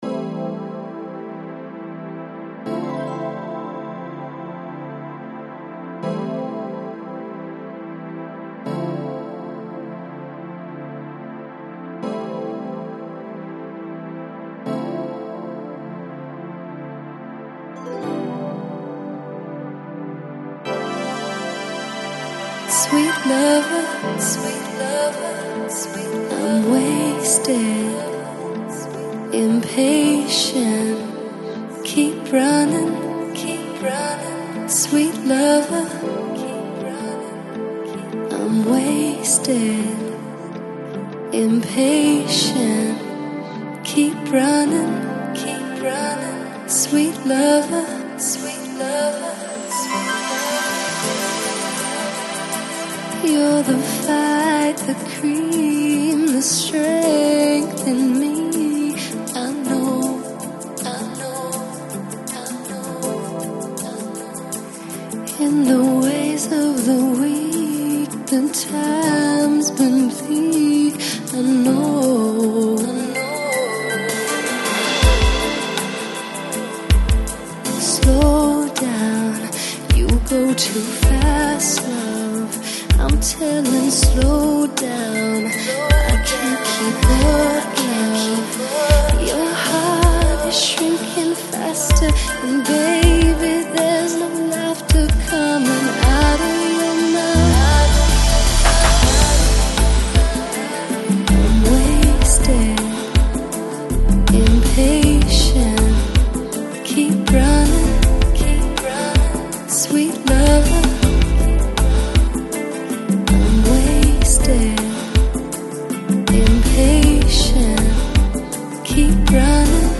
Жанр: Synthpop, Dreampop